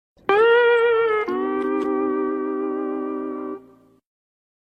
Spongebob Sad Sound - Bouton d'effet sonore